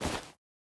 Minecraft Version Minecraft Version 25w18a Latest Release | Latest Snapshot 25w18a / assets / minecraft / sounds / item / bundle / insert2.ogg Compare With Compare With Latest Release | Latest Snapshot